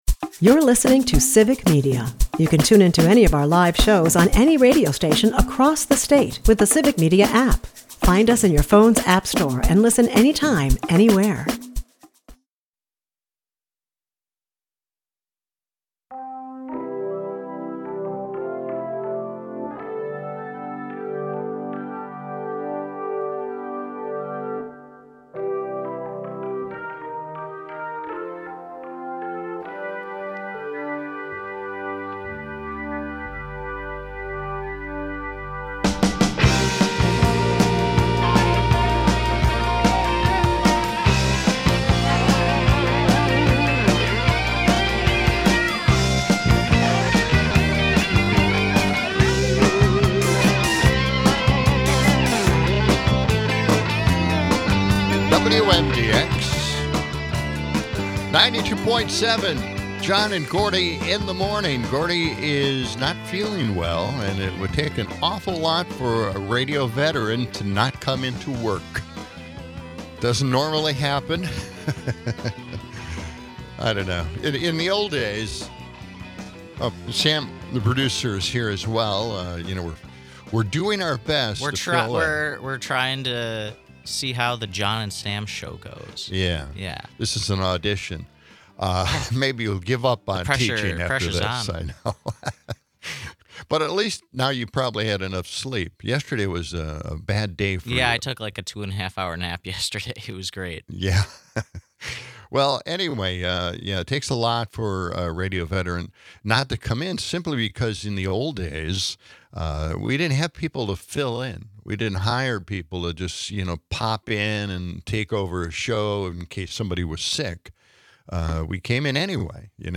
A blend of humor, nostalgia, and sharp critique on tech and politics.